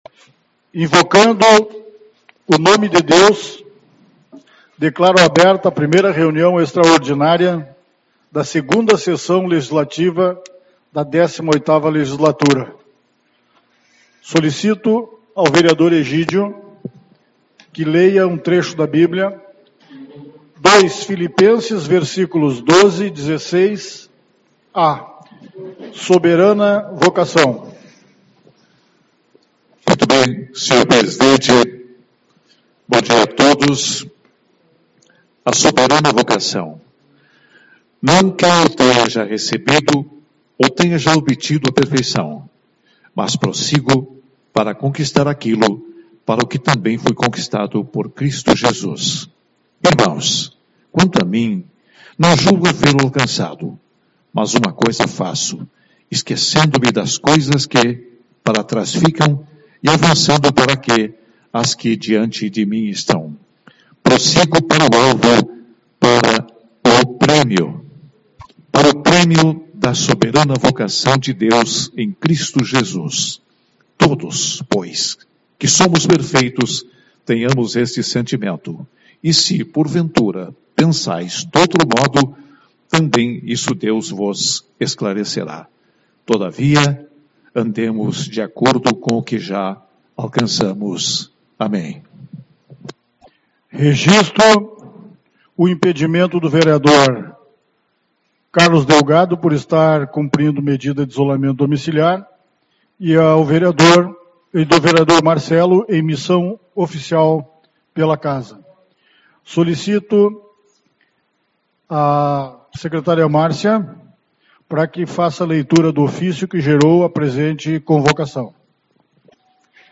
28/01 - Reunião Extraordinária